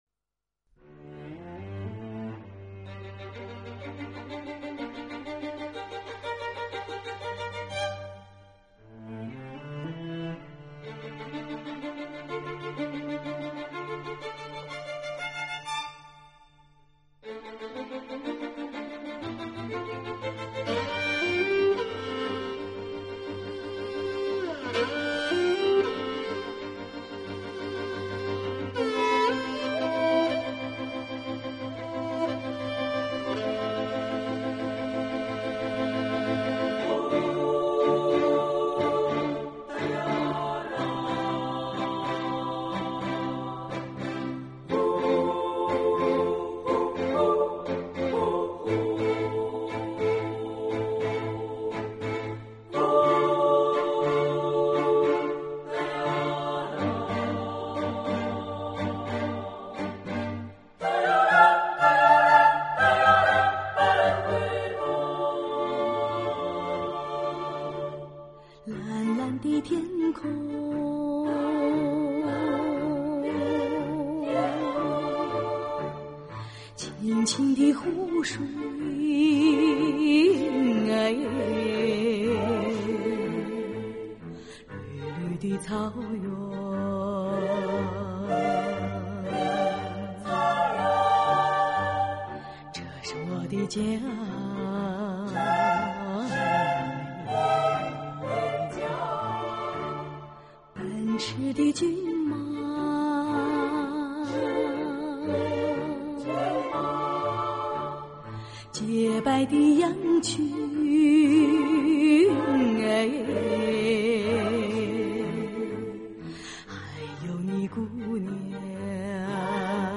女声领唱与女声四重唱